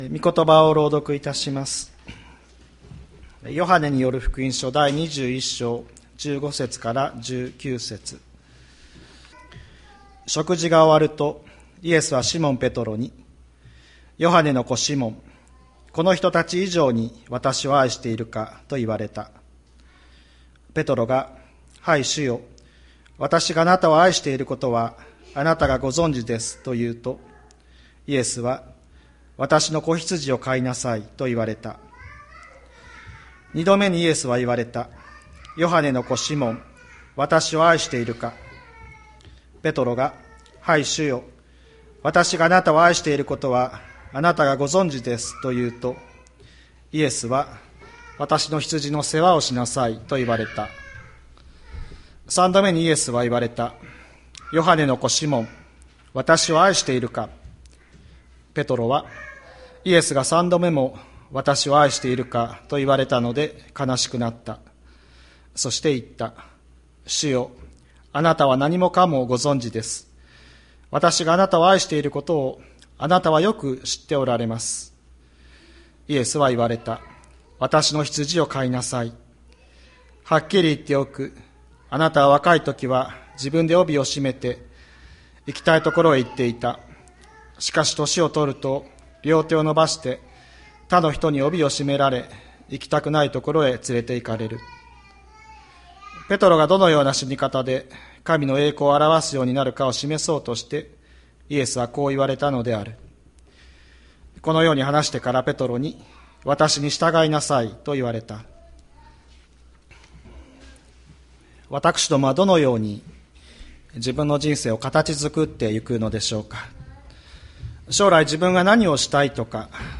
2023年05月14日朝の礼拝「もう一度、愛の中へ！」吹田市千里山のキリスト教会